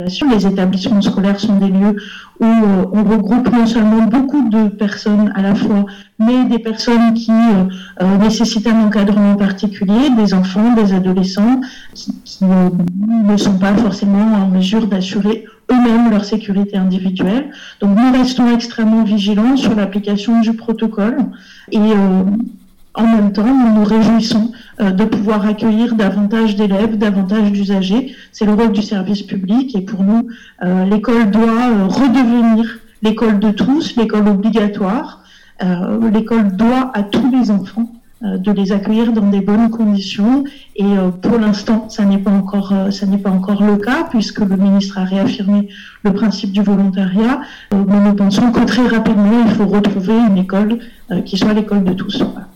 Entretiens.